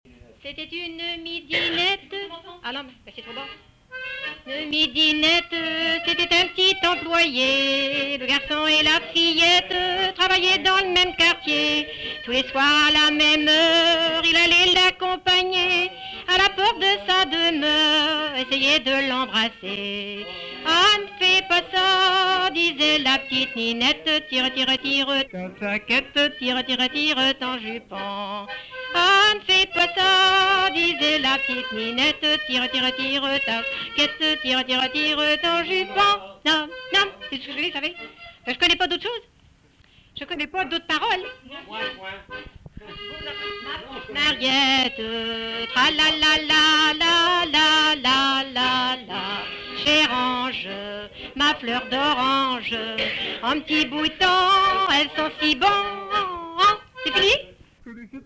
Avec accordéon